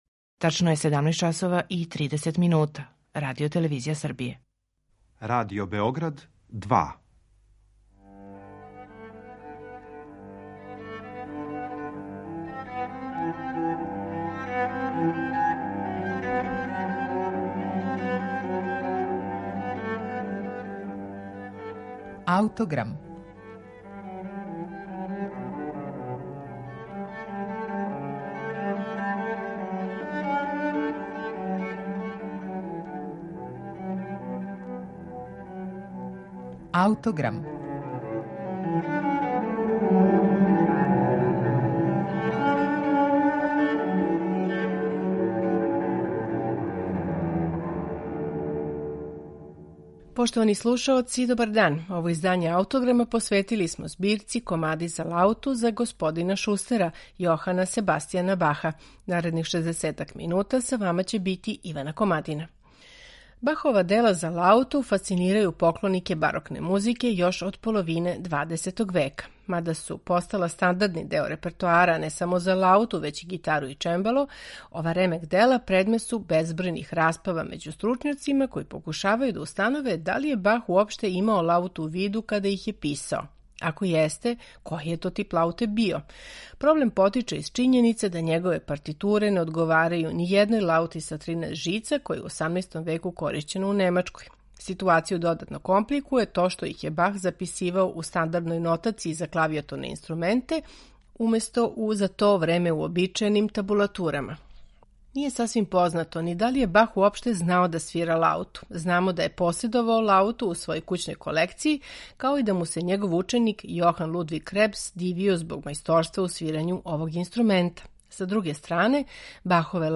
Чућемо је у интерпретацији лаутисте Пола О'Дета.